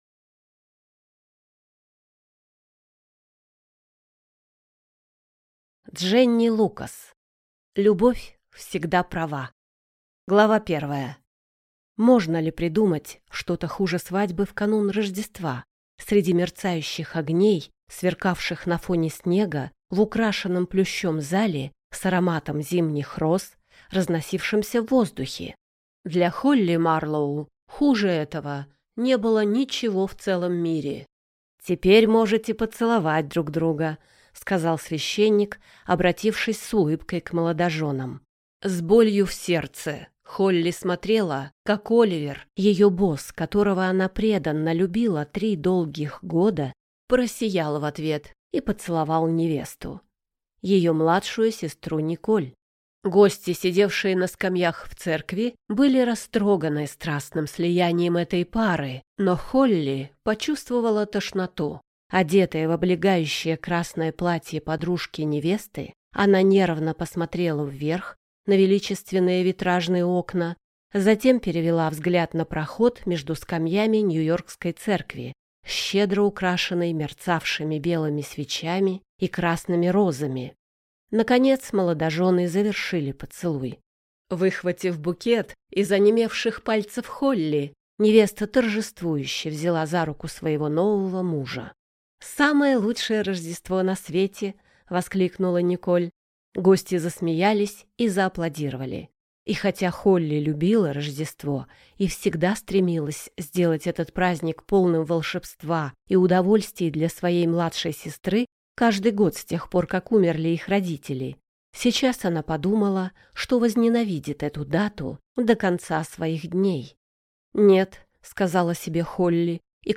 Аудиокнига Любовь всегда права | Библиотека аудиокниг